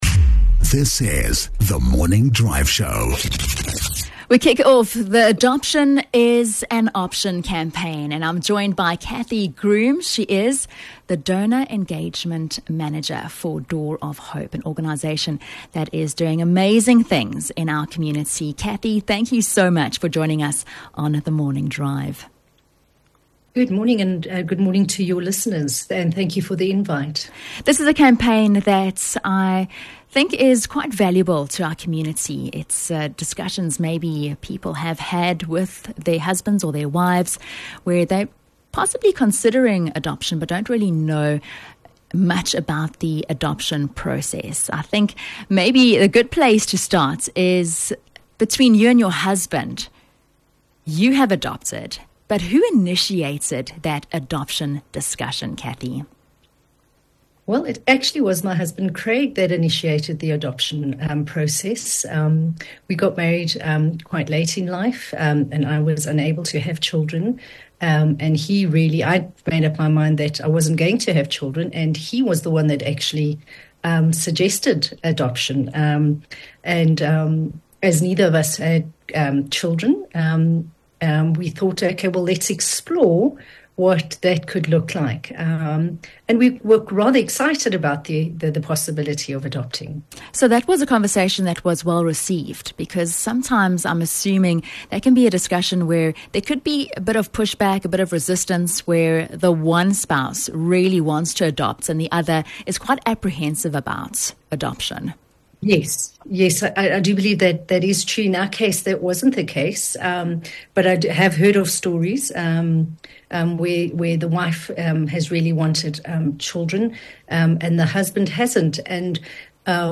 Interview - Impact 103